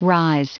Prononciation du mot rise en anglais (fichier audio)
Prononciation du mot : rise